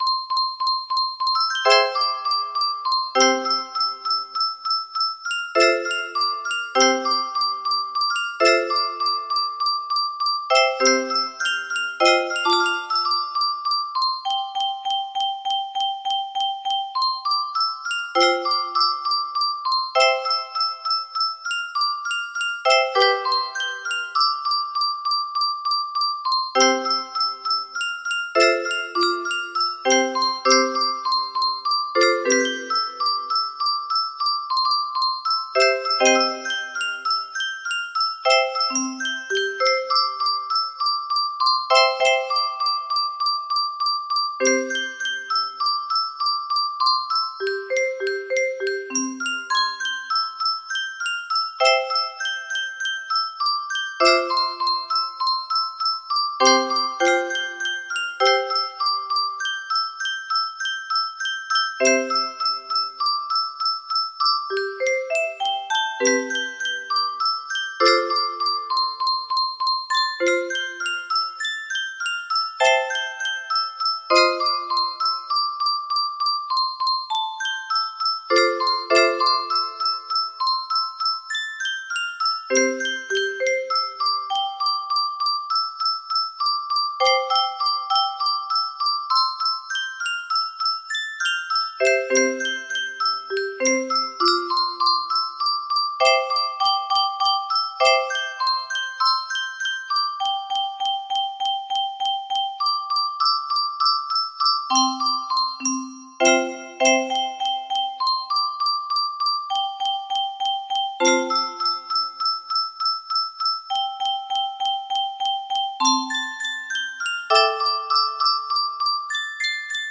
Imported from MIDI from imported midi file (9).mid